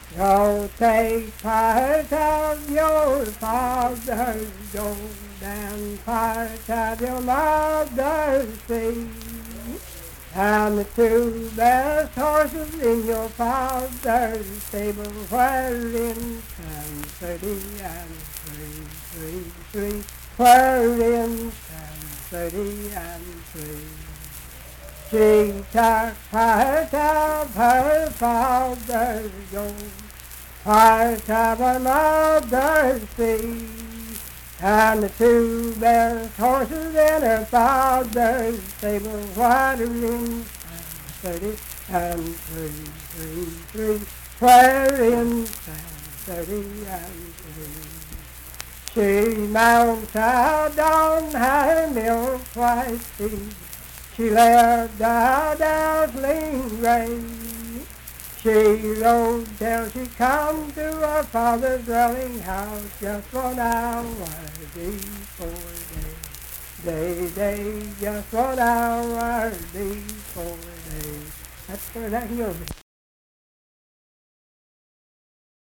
Unaccompanied vocal music
Performed in Ivydale, Clay County, WV.
Voice (sung)